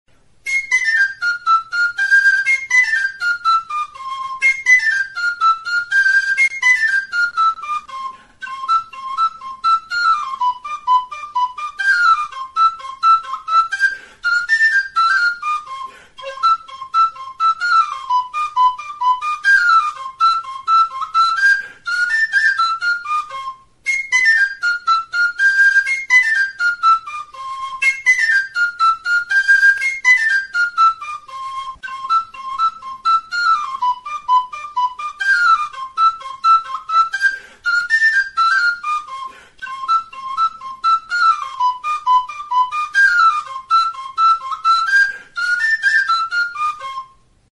Aerophones -> Flutes -> Fipple flutes (two-handed) + kena
Recorded with this music instrument.
WHISTLE; Flauta
Bi eskuko flauta zuzena da.
C tonuan afinaturik dago.